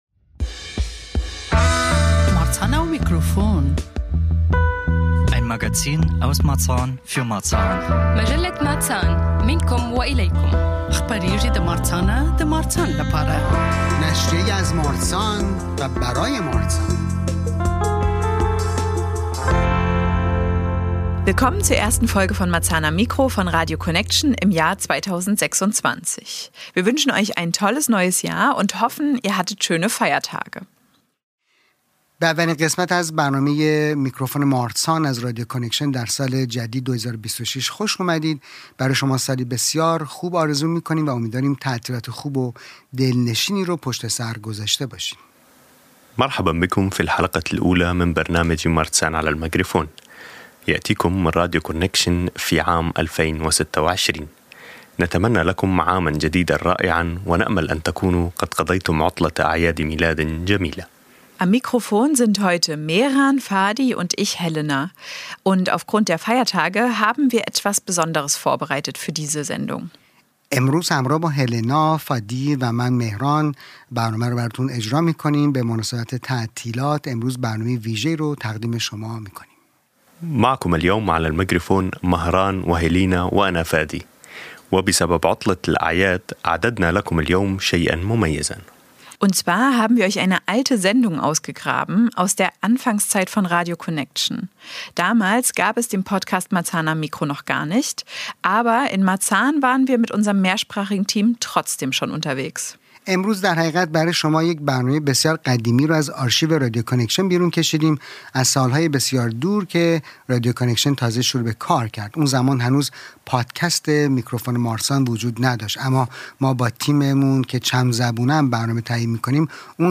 Beschreibung vor 3 Monaten (deutsch/farsi/arabisch) Zum Beginn des neuen Jahres haben wir für Euch eine alte Sendung ausgegraben aus dem Jahr 2019 – der Anfangszeit von radio connection.